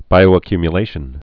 (bīō-ə-kymyə-lāshən)